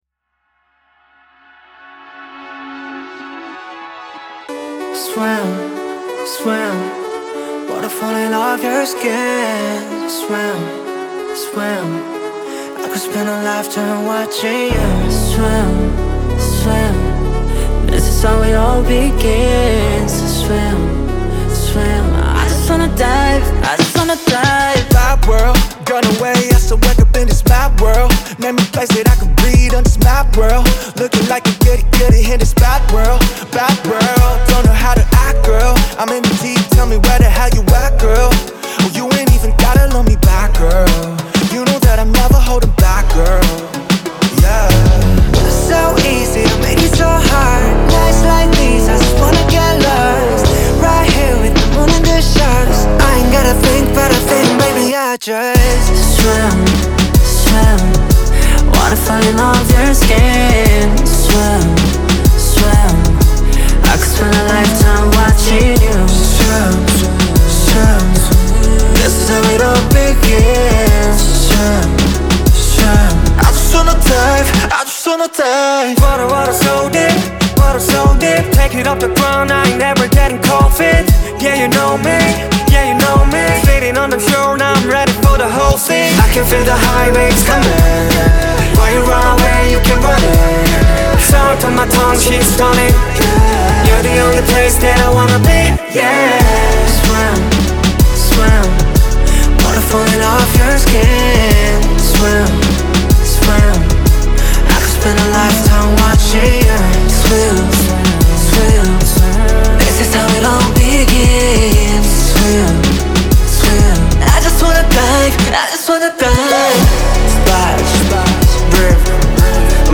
BPM94-94
Audio QualityPerfect (High Quality)
R&B / K-Pop song for StepMania, ITGmania, Project Outfox
Full Length Song (not arcade length cut)